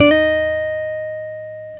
Guitar Slid Octave 16-D#3.wav